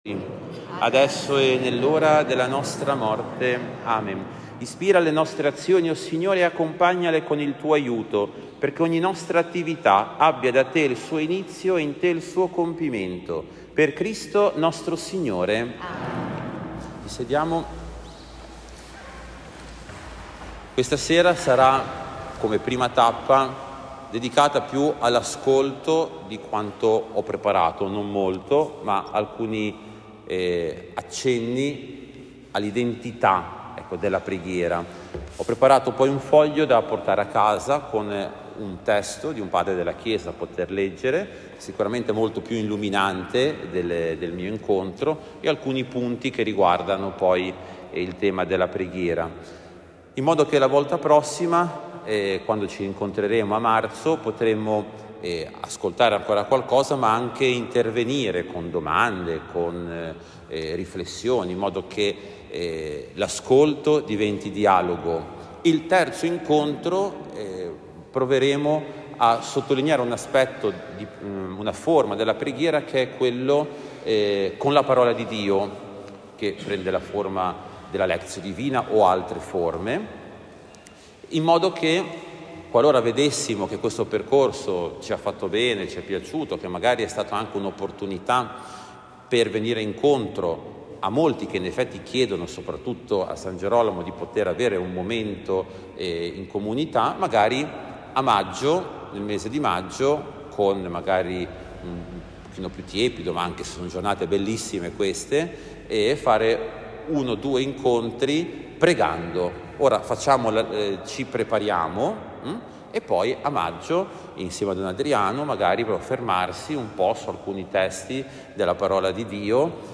Catechesi sulla Preghiera